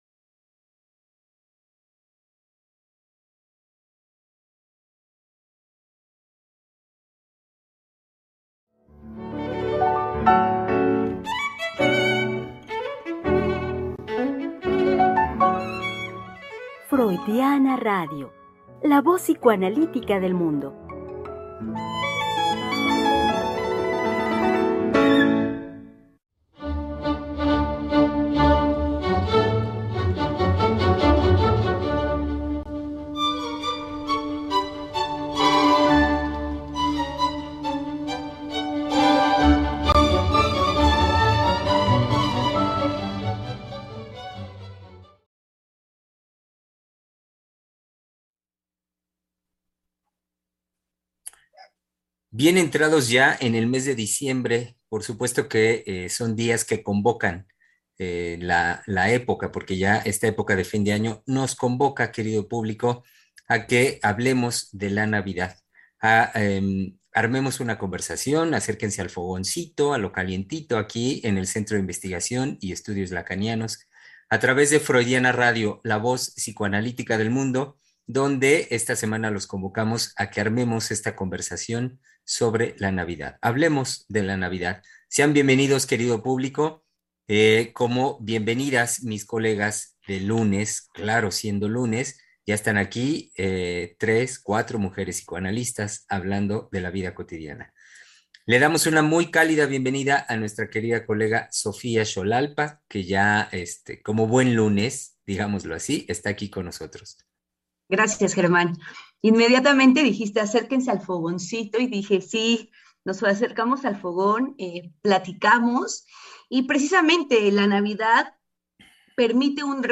Programa transmitido el 6 de diciembre del 2021.